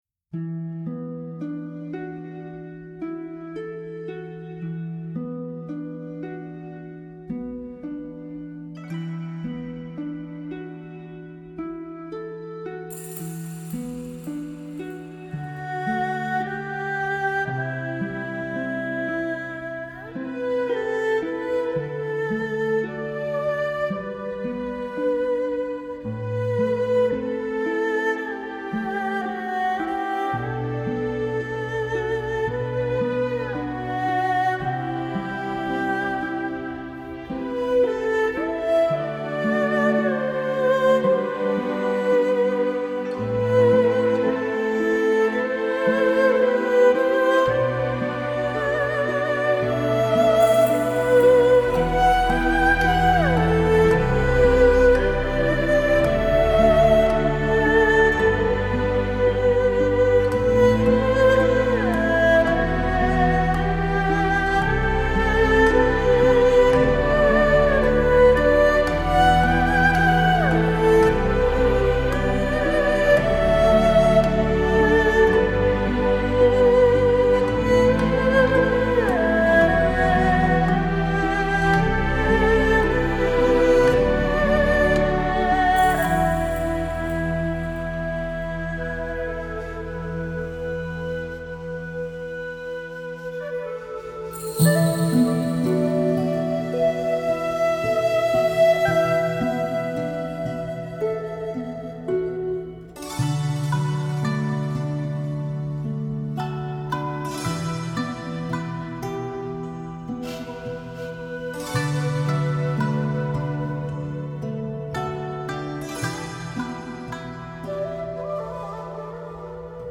آرامش بخش , بومی و محلی , ملل , موسیقی بی کلام